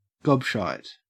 Ääntäminen
IPA : /ˈɡɒbˌʃaɪt/